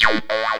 tekTTE63039acid-A.wav